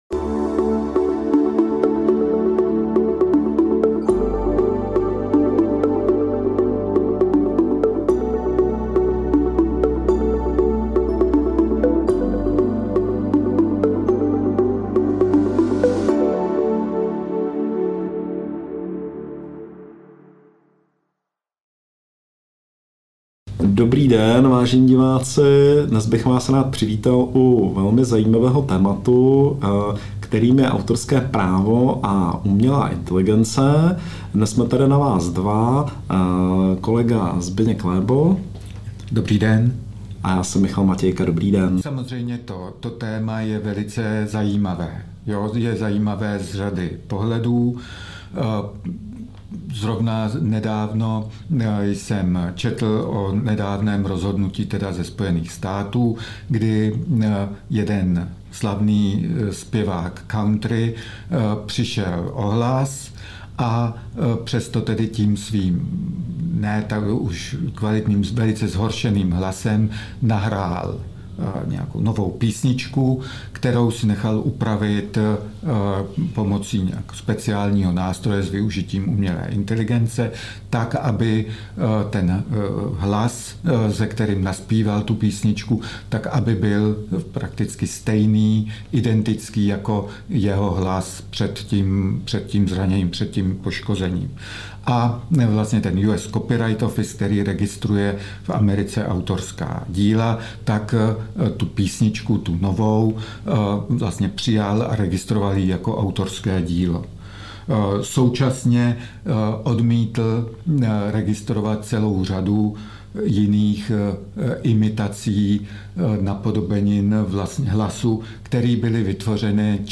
Ale vše, co vytvoří umělá inteligence, autorský zákon nechrání - Právo k Ranní Kávě Oct 15, 03:30 AM Headliner Embed Embed code See more options Share Facebook X Subscribe Nový díl talkshow Na kus řeči s právníky se zabývá problematikou autorského práva v kontextu umělé inteligence (AI) a analyzuje, jak současný právní rámec – zejména český – reaguje na nové technologické výzvy. Hlavním tématem je otázka, zda mohou být výtvory vytvořené umělou inteligencí chráněny autorským právem, a pokud ano, za jakých podmínek.